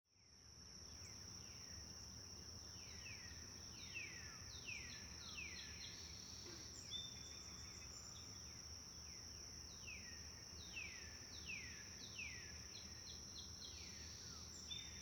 Woods 03
Animal Sound Effects, Background Sound Effects
woods_03-1-sample.mp3